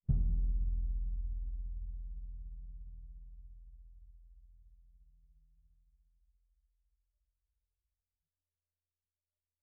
bassdrum_hit_mp2.wav